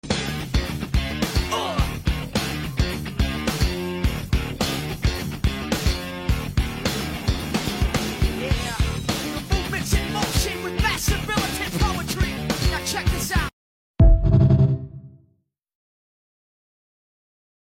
Ineos greanadier subwoofer enclosure.